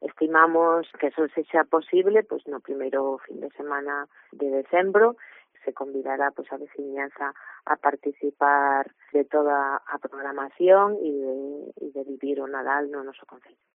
La concejala de Cultura de Poio, sobre la previsión del día de inauguración de las luces de Navidad 2022